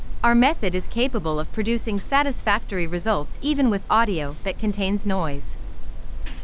noisy_audio_office_snr_0.wav